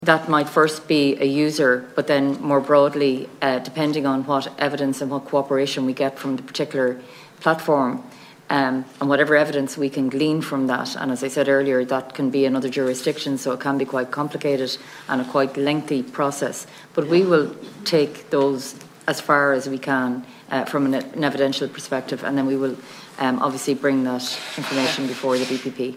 Assistant Garda Commissioner Angela Willis, says investigators will follow the evidence: